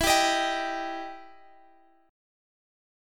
Listen to EmM7 strummed